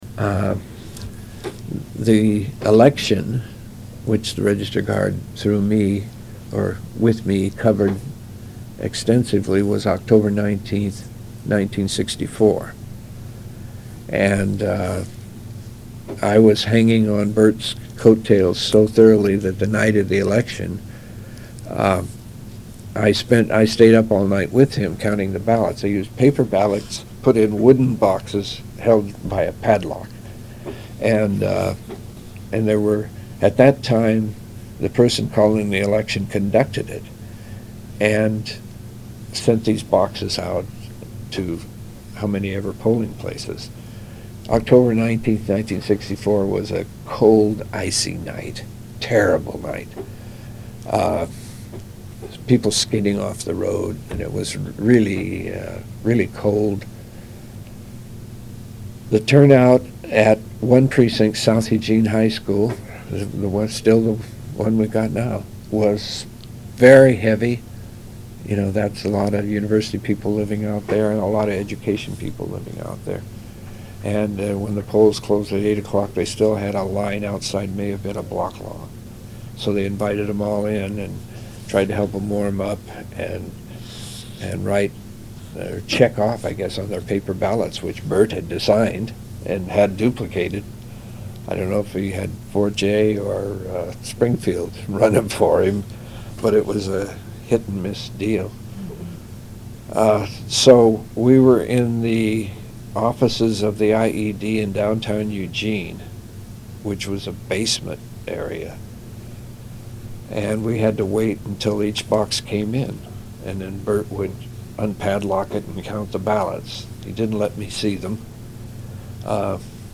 History: Online Exhibits / Oral History